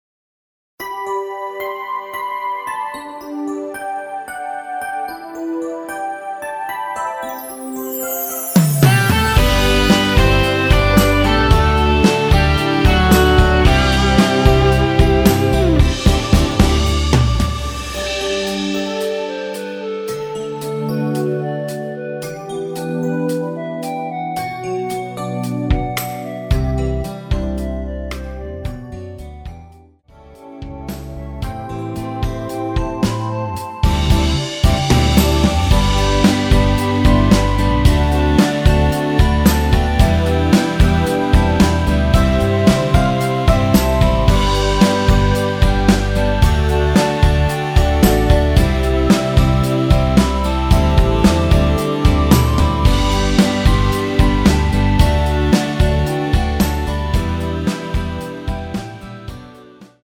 원키 멜로디 포함된 MR입니다.
멜로디 MR이란
앞부분30초, 뒷부분30초씩 편집해서 올려 드리고 있습니다.